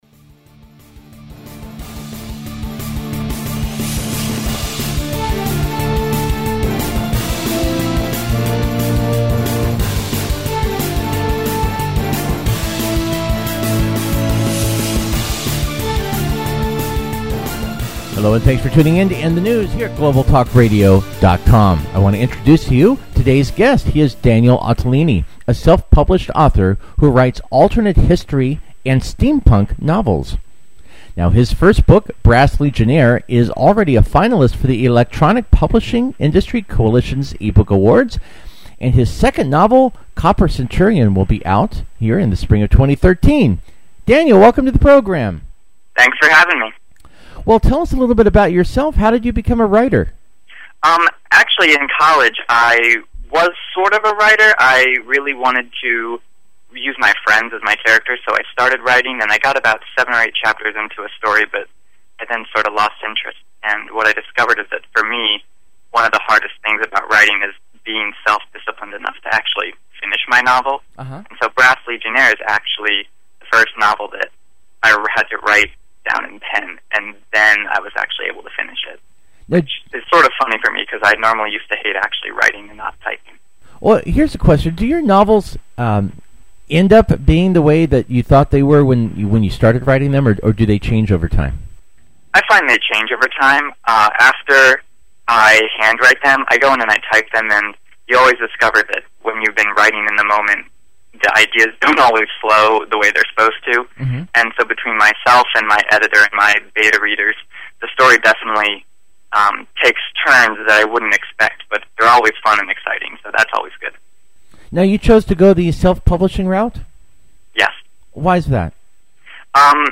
Radio Interview Link & Last Day for Kickstarter!
radiointerview.mp3